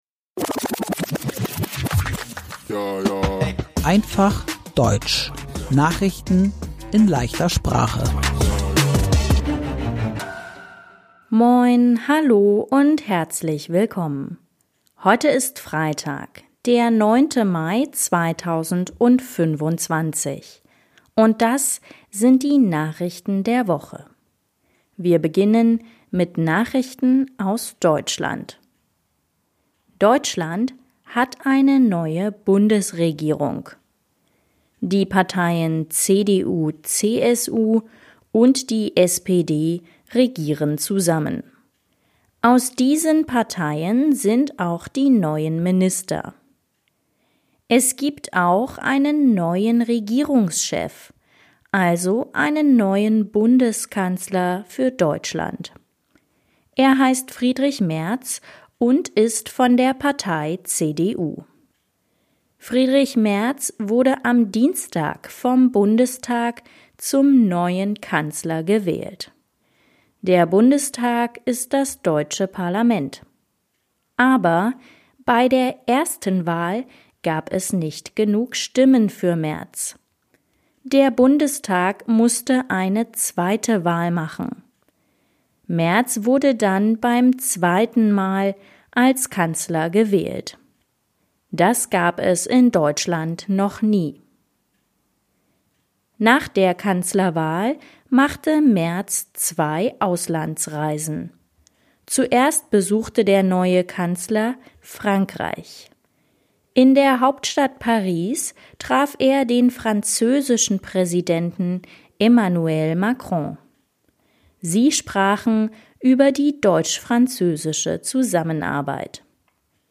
Einfach Deutsch: Nachrichten in leichter Sprache